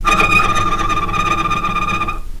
healing-soundscapes/Sound Banks/HSS_OP_Pack/Strings/cello/tremolo/vc_trm-D#6-pp.aif at bf8b0d83acd083cad68aa8590bc4568aa0baec05
vc_trm-D#6-pp.aif